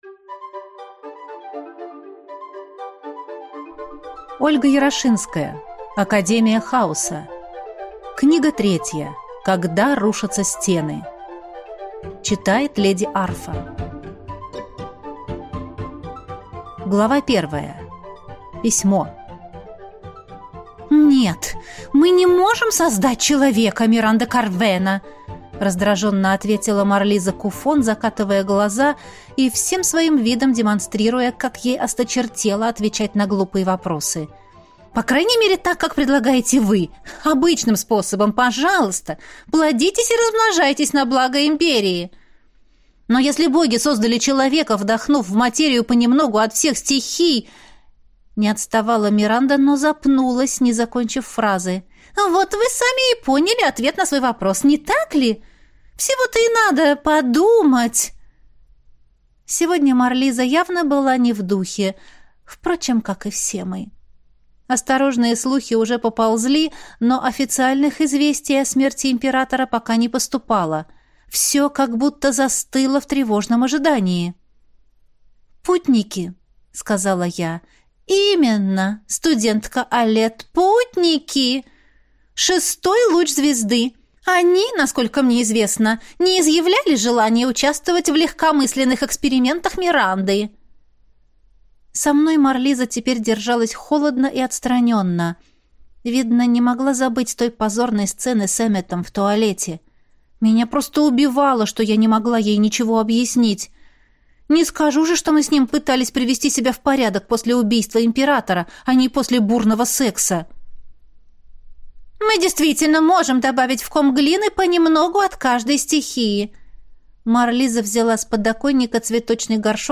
Аудиокнига Когда рушатся стены | Библиотека аудиокниг